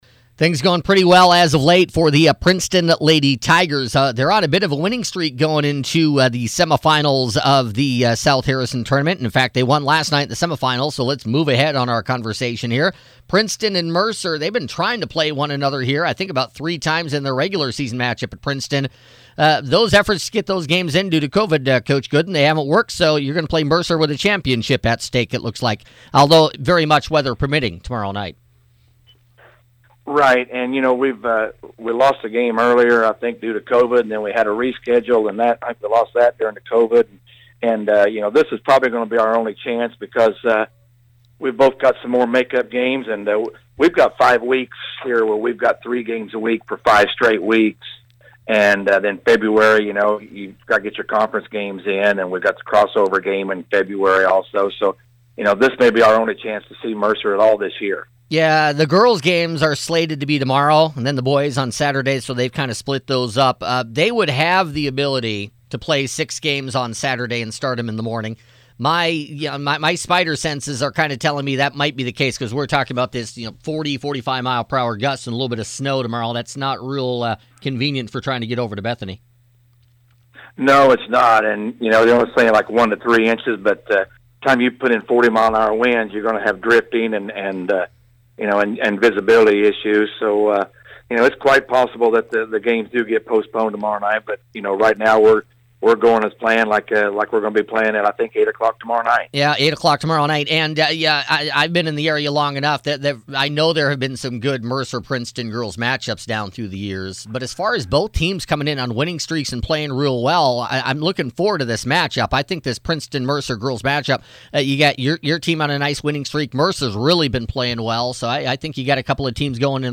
Here are the latest interviews with coaches